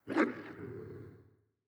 SFX_Wolf_Bark_01.wav